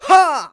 xys普通7.wav 0:00.00 0:00.54 xys普通7.wav WAV · 47 KB · 單聲道 (1ch) 下载文件 本站所有音效均采用 CC0 授权 ，可免费用于商业与个人项目，无需署名。
人声采集素材